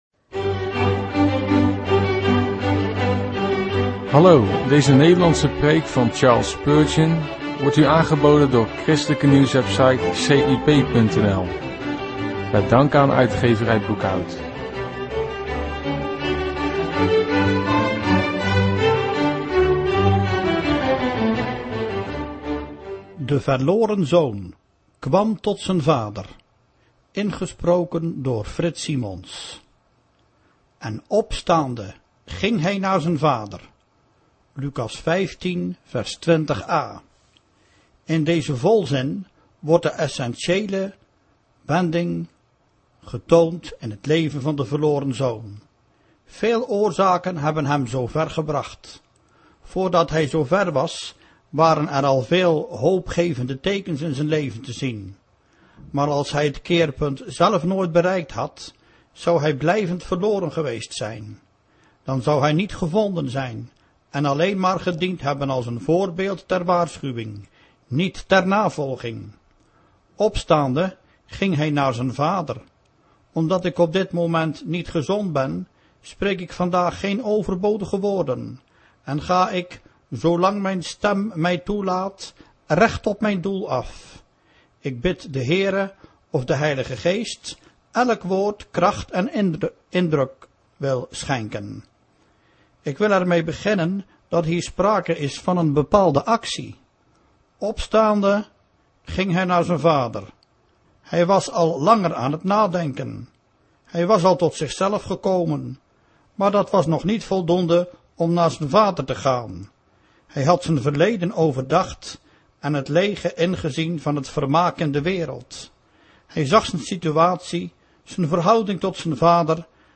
Een preek over 'De verloren zoon kwam tot de Vader'. Spreker: Charles H. Spurgeon